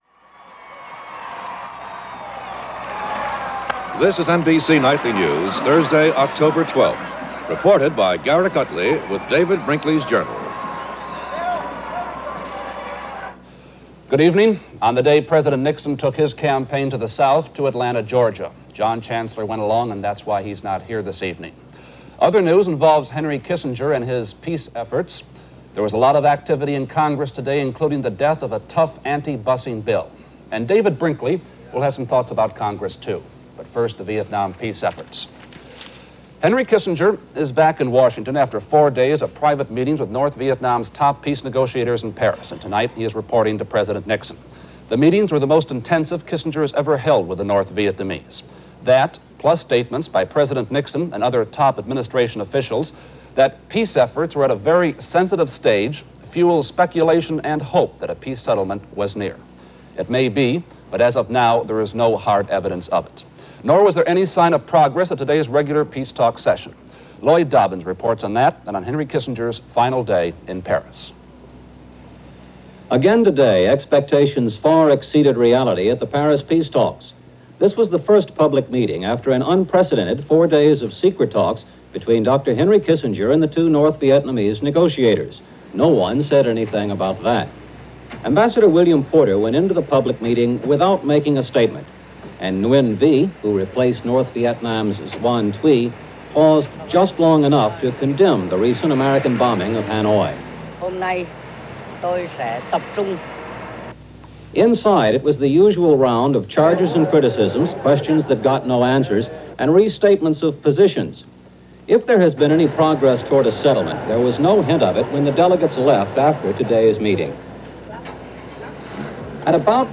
NBC Nightly News